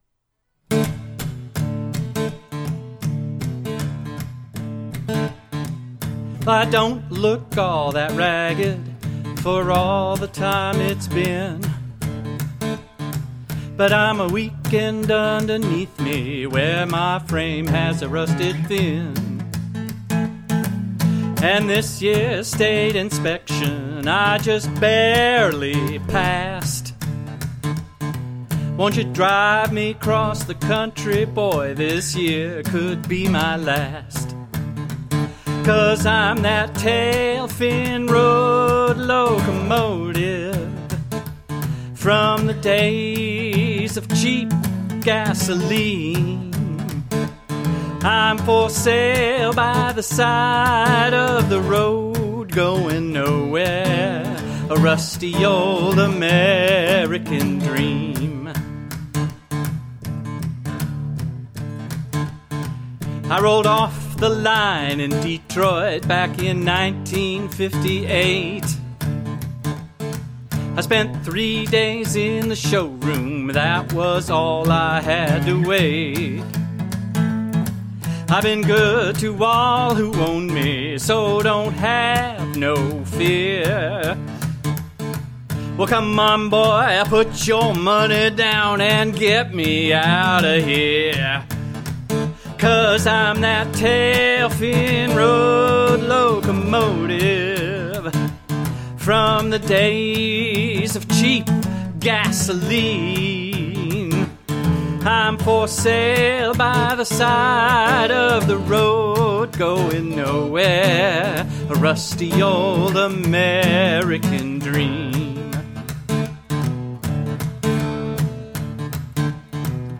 It contains the main guitar part and some vocals.